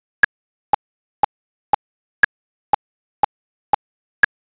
That’s my Mac in the back garden setting up for overdubbing with a Behringer UM2, earbuds and a ratty AKG PA system microphone.
The sound clip is after a second pass at correction where the lineup is very nearly perfect.
I can hear my fingers rubbing on the microphone as I hold up the earbud during the recording.